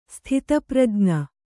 ♪ sthita prajña